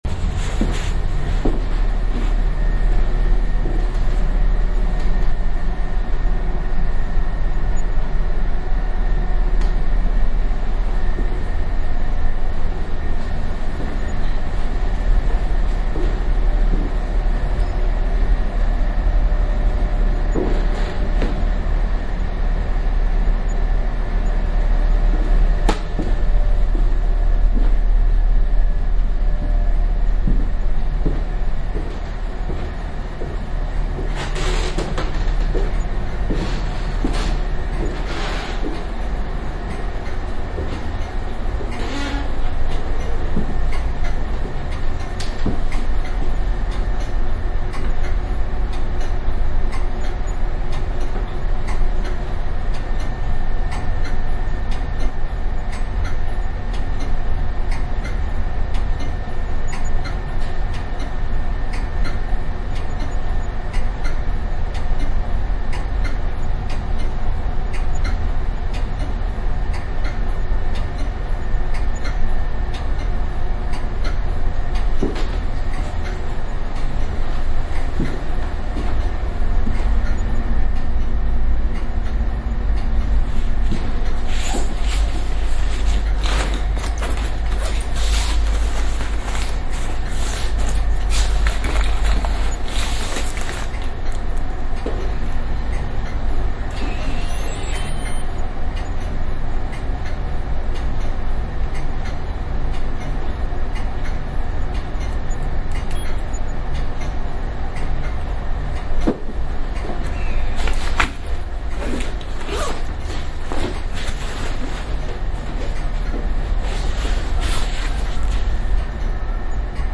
描述：宏伟的新市政厅始建于20世纪初。要想乘坐对角线电梯登上穹顶，欣赏汉诺威的美景，有时需要等待，在这种情况下，要等上30分钟，其中一些等待的过程是我用Soundman OKM II录音室和他们的DR2录音机录制的
标签： 大气 双耳 人群 现场录音 汉诺威 市政厅 市政厅
声道立体声